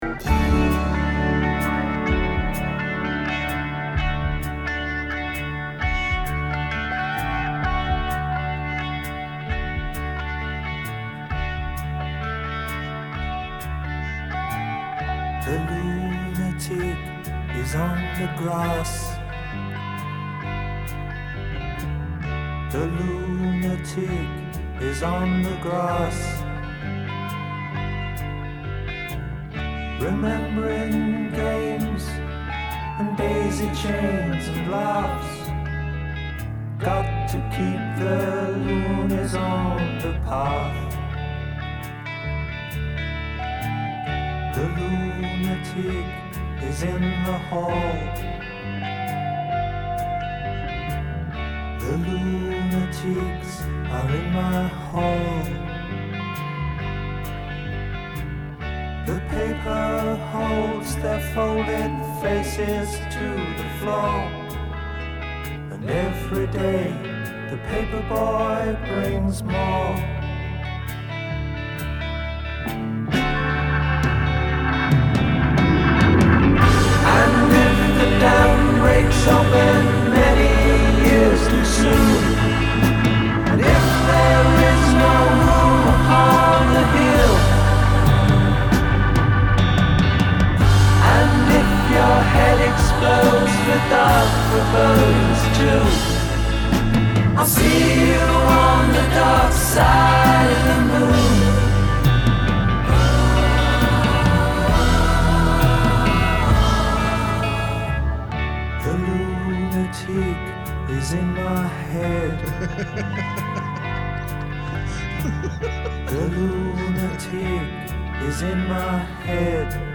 rock music